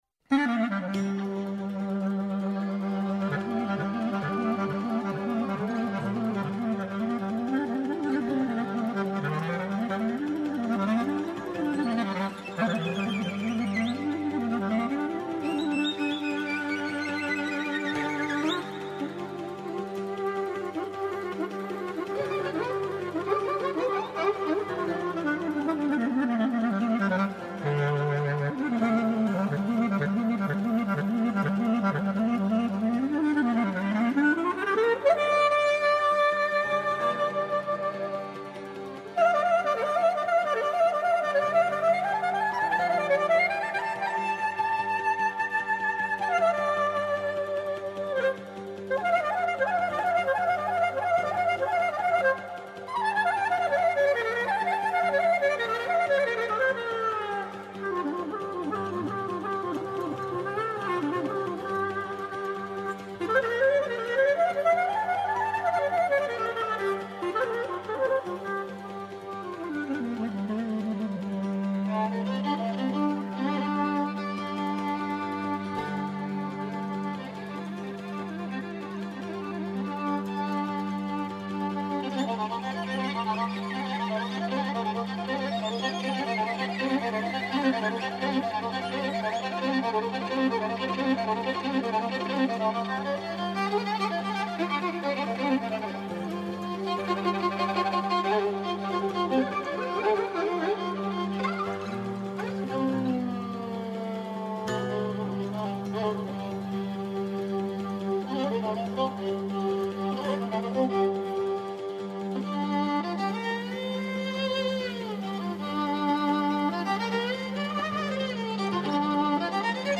Ένα καθημερινό μουσικό ταξίδι στην παράδοση της Ελλάδας. Παλιές ηχογραφήσεις από το αρχείο της Ελληνικής Ραδιοφωνίας, νέες κυκλοφορίες δίσκων καθώς και νέες ηχογραφήσεις στο Στούντιο των Μουσικών Συνόλων της ΕΡΤ.